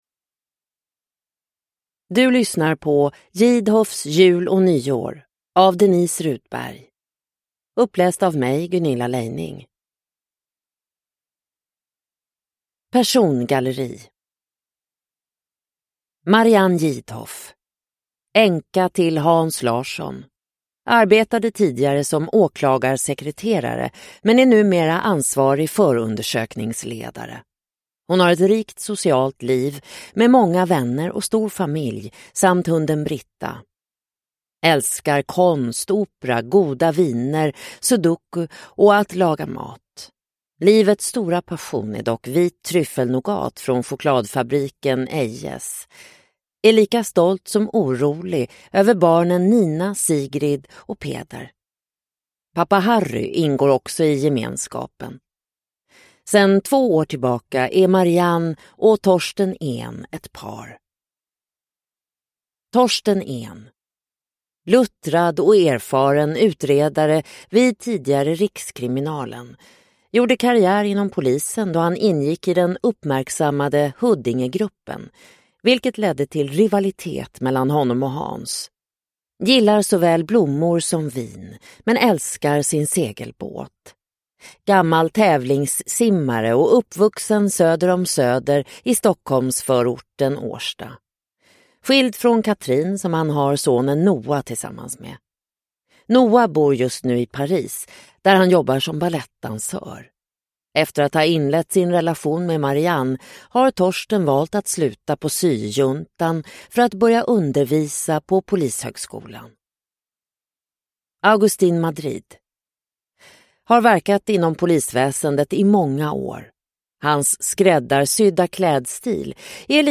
JIdhoffs jul och nyår – Ljudbok – Laddas ner